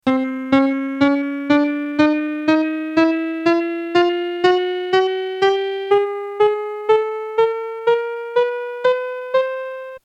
microtonal scales (pitches are closer together)
Microtonal.mp3